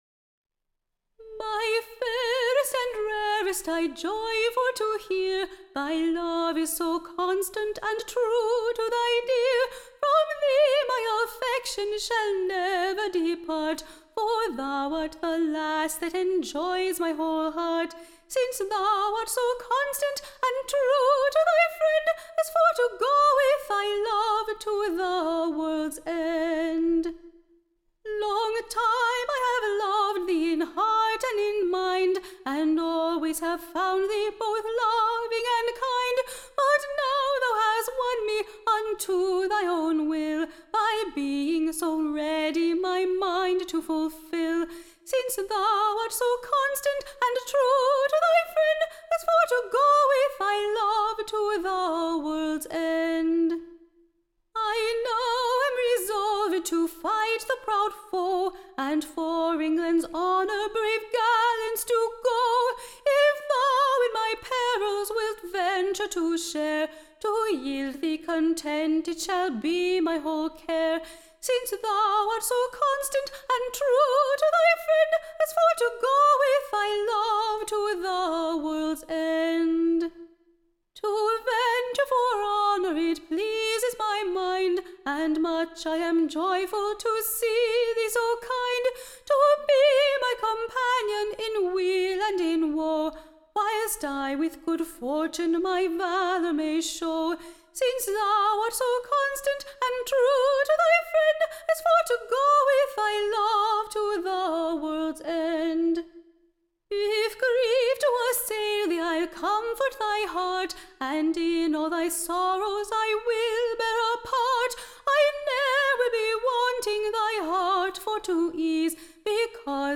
Recording Information Ballad Title The VOLUNTEERS kind Answer, / TO / The Loyal Damosels Resolution.